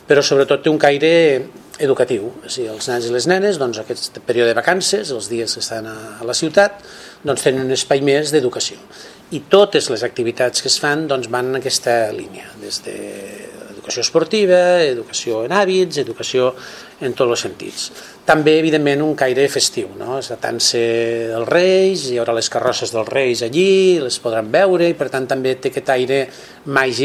tall-de-veu-del-regidor-jesus-castillo-sobre-la-presentacio-del-23e-cucalocum-i-8e-cucaesport